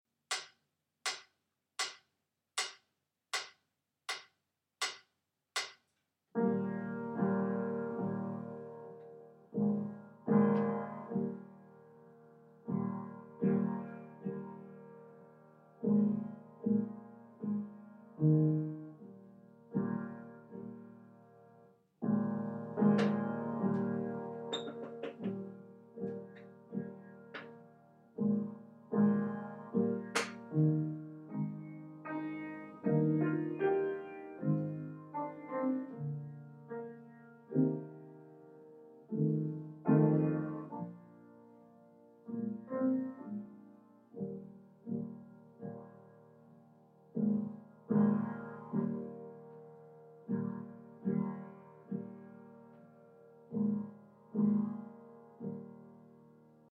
Prelude Piano Part